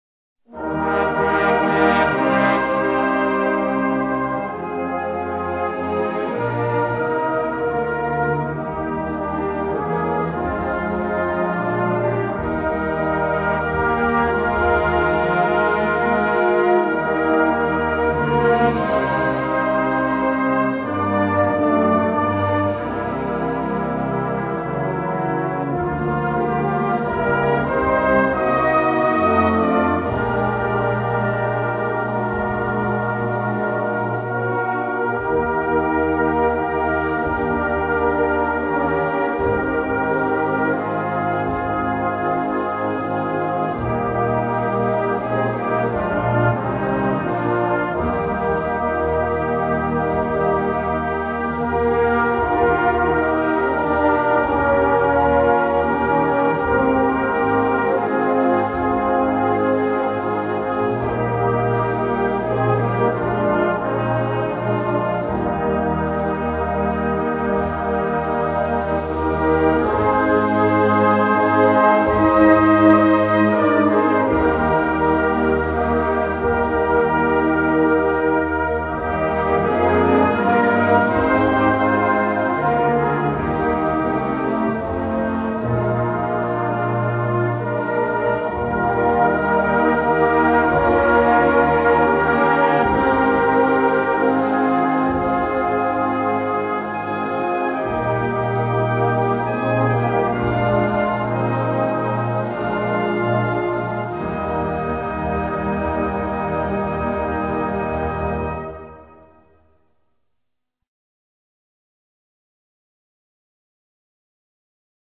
Key: E♭
Tempo: 120